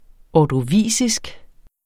Udtale [ ɒdoˈviˀsisg ]